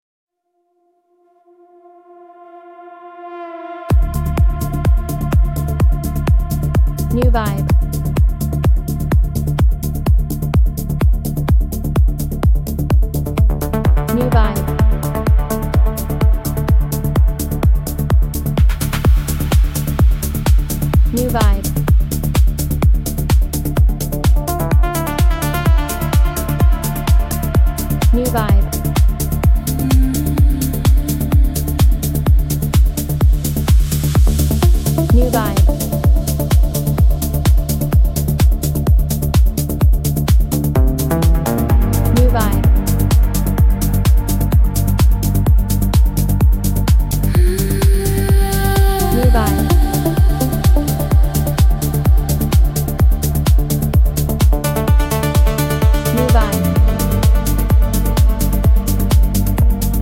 Genre: Electronic beat